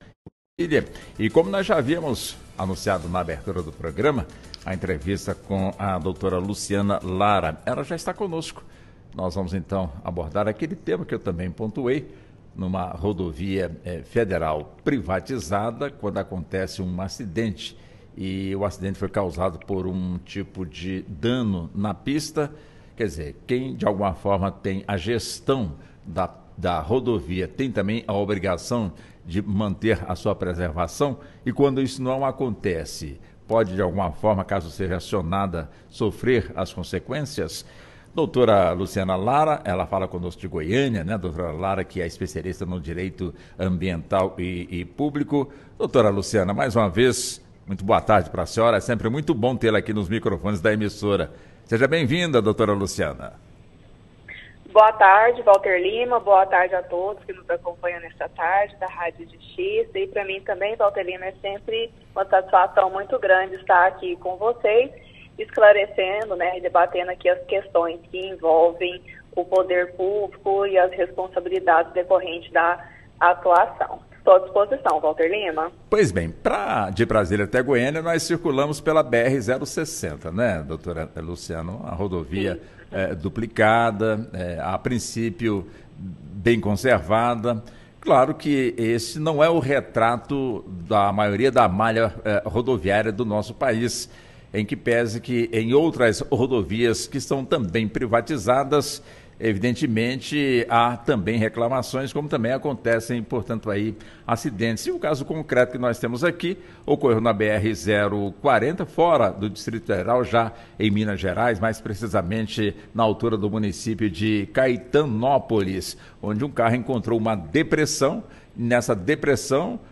Em entrevista à Rádio Justiça